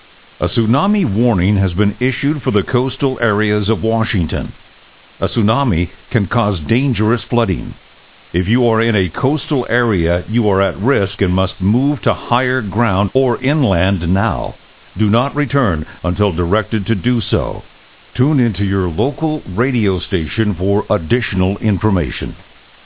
Evacuation Siren
Actual Message